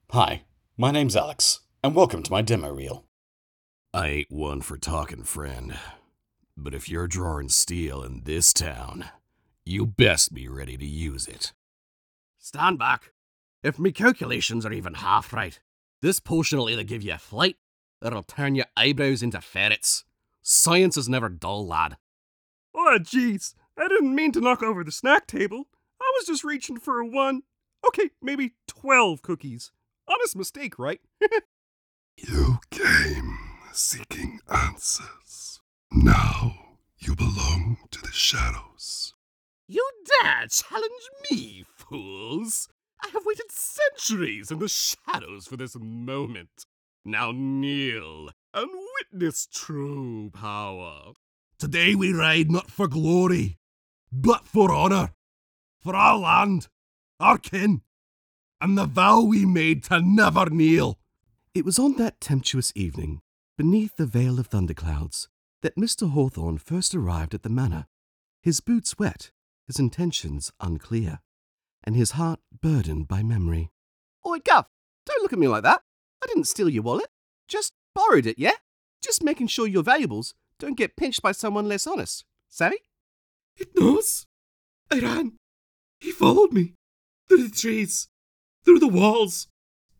Male
Adult (30-50), Older Sound (50+)
A naturally deep voice with surprising range; equally ready to roar, whisper, or narrate your next masterpiece.
Character / Cartoon
All our voice actors have professional broadcast quality recording studios.